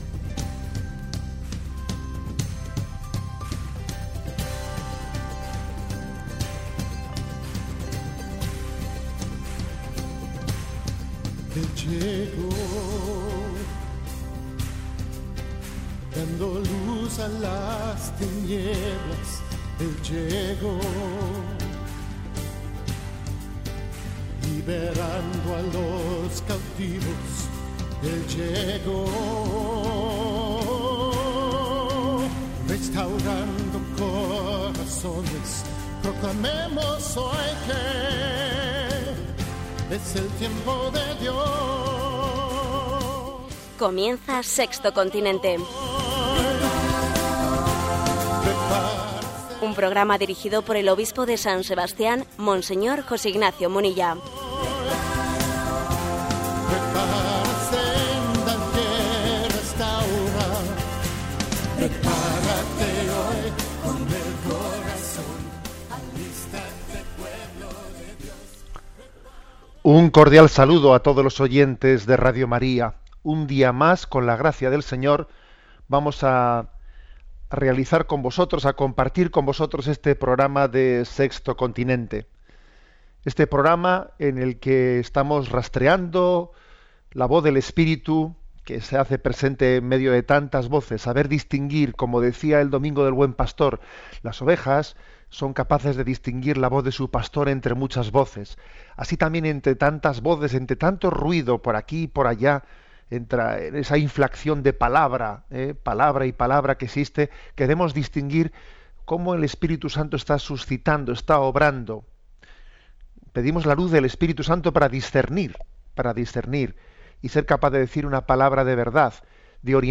Careta del programa, presentació, saber distingir la veu de l'Esperit Sant entre totes les veus, demanda de donatius a la recapta del mes de maig per manternir Radio María, resposta a les preguntes de l'audiència